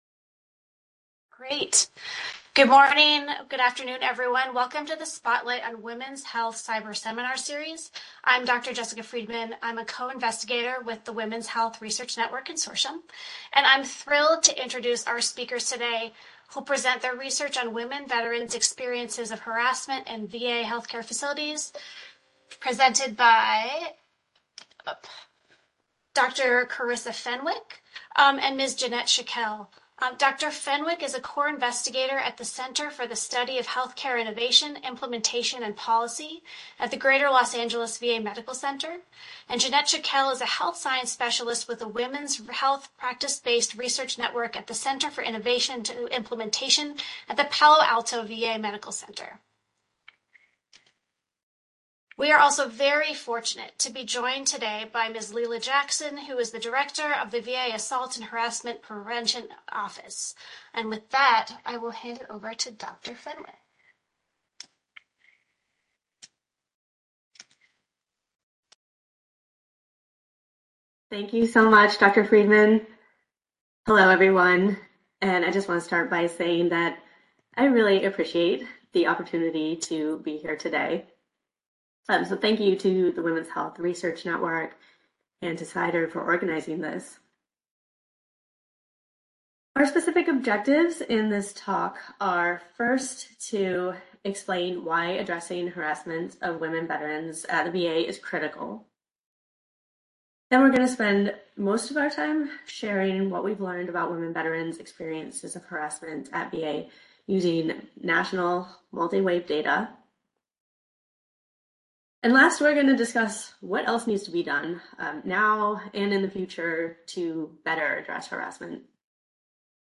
This seminar uses national data collected by the VA Women’s Health Practice-Based Research Network from 2017-2022 to examine harassment prevalence, perpetrators of harassment, women Veterans’ perceptions of safety, and staff intervention in harassment at VA. Discussion includes current efforts and future plans to understand, prevent, and address harassment at VA.